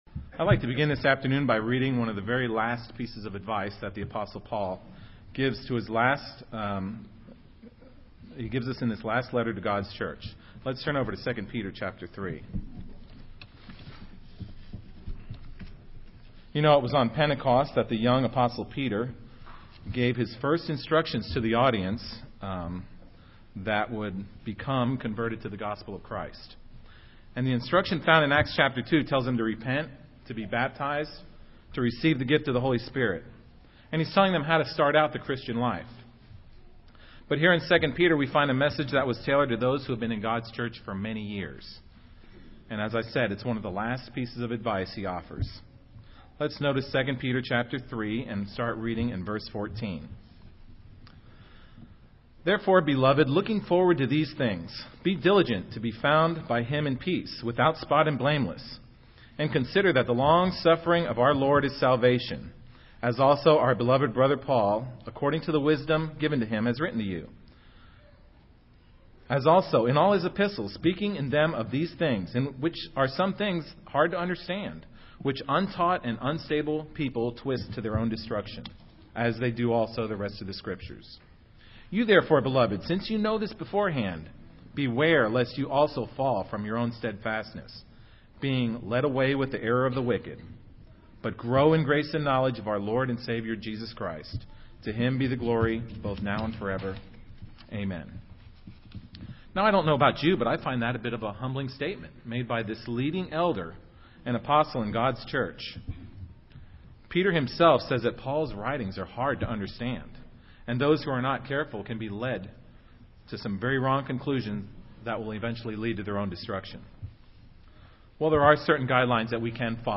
Sermons
Given in Dallas, TX Lawton, OK